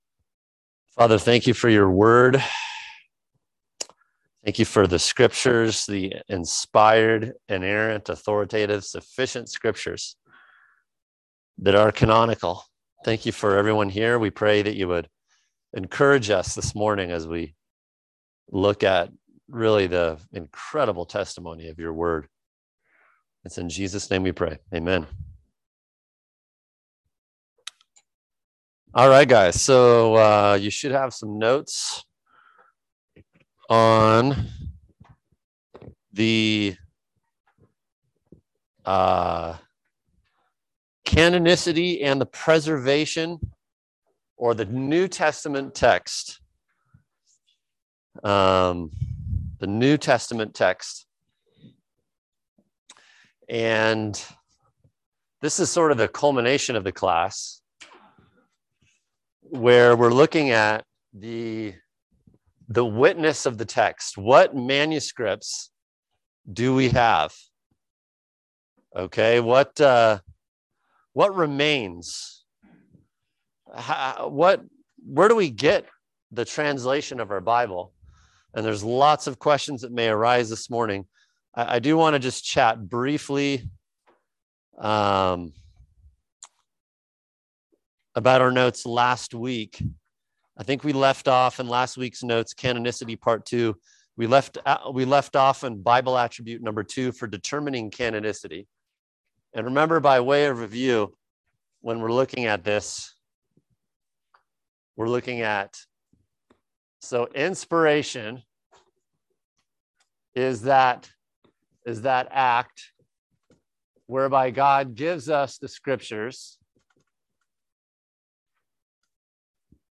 [equipping class] Bibliology Lesson 8 Pt 2: Canonicity – How Do we Know What Belongs in the Bible?